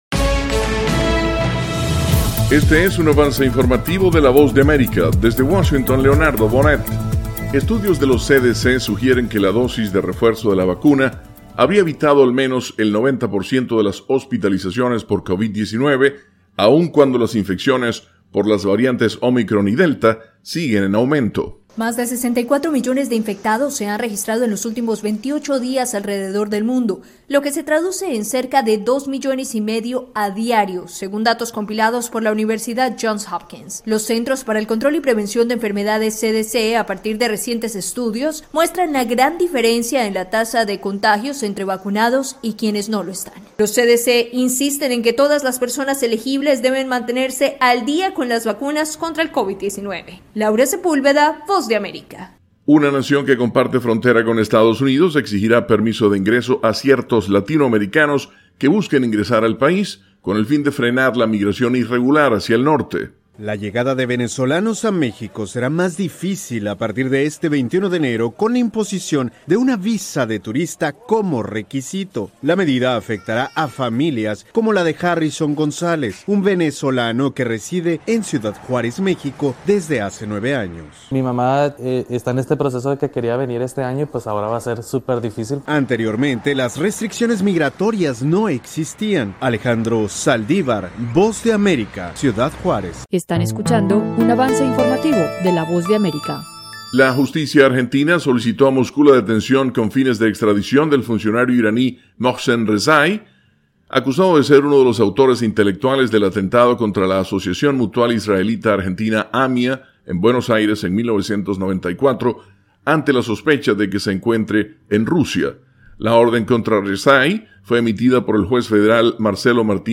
Resumen informativo con algunas de las noticias más importantes de Estados Unidos y el resto del mundo.